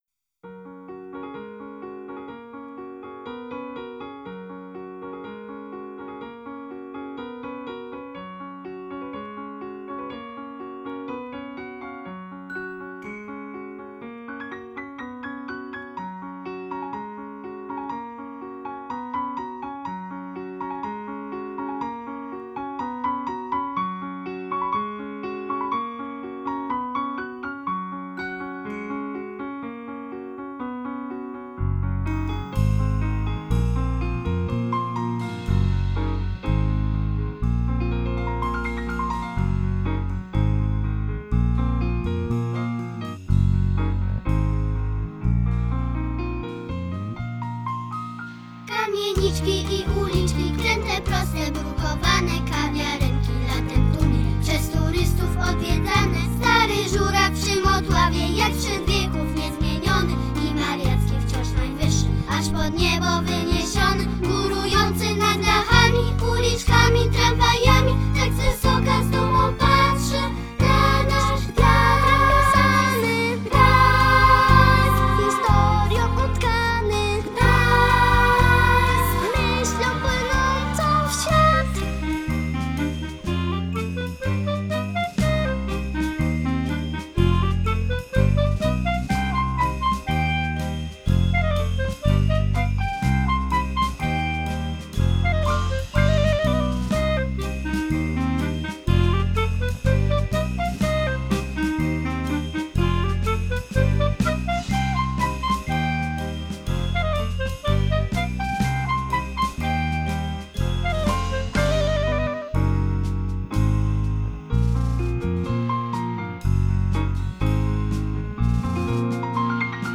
Kamieniczki i uliczki – piosenka gdańskich przedszkolaków